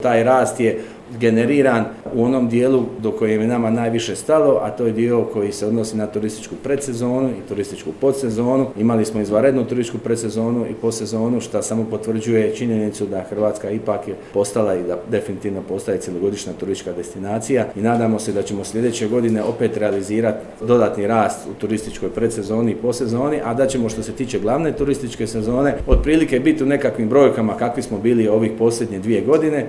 Ministar turizma i sporta Tonči Glavina: